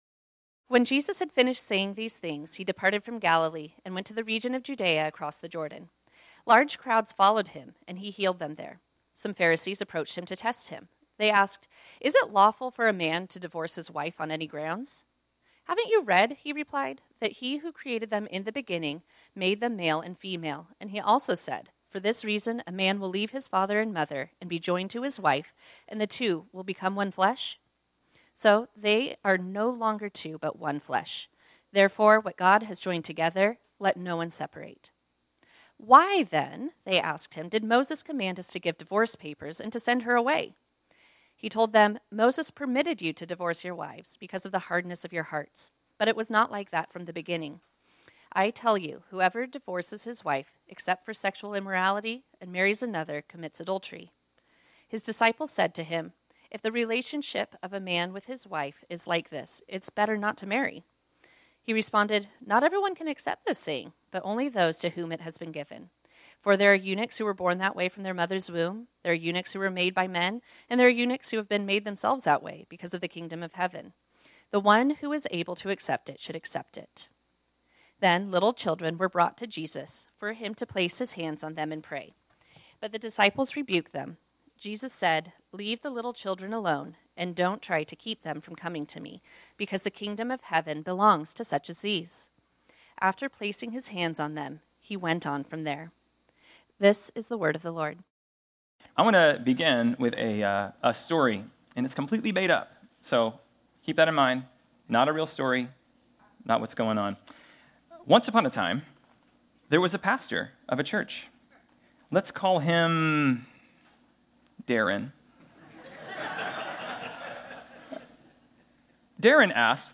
This sermon was originally preached on Sunday, September 1, 2024.